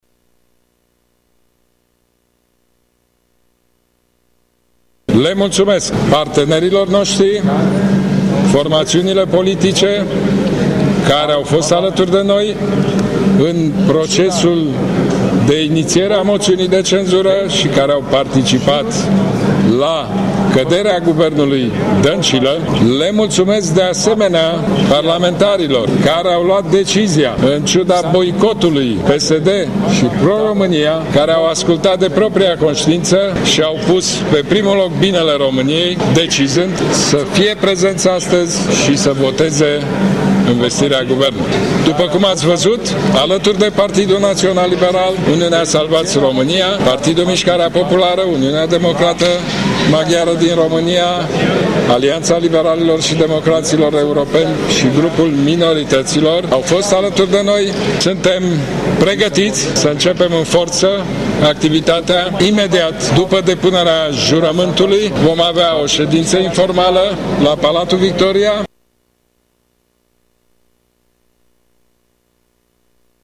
După anunțarea rezultatului oficial, noul premier a mulțumit tuturor formațiunilor politice care au votat pentru noul Guvern: